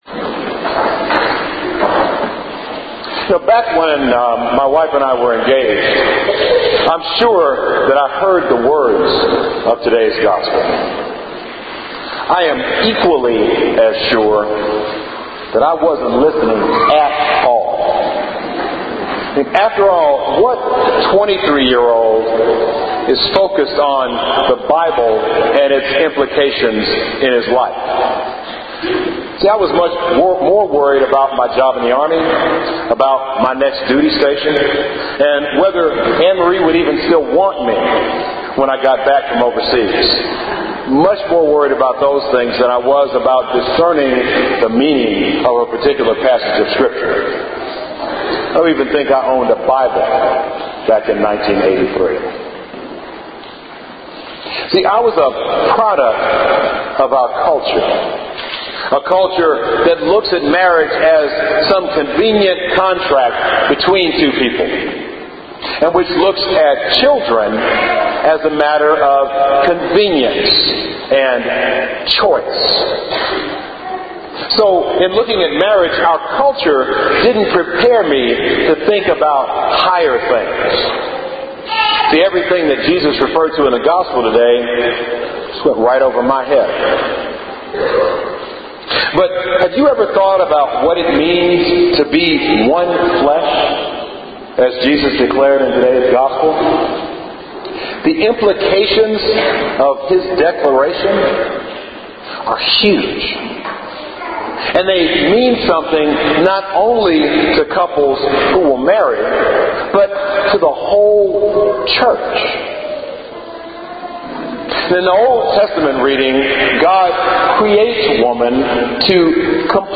Homily for the 27th Sunday in Ordinary Time – “One Flesh”
homily-27th-sunday-in-ordinary-time-one-flesh.mp3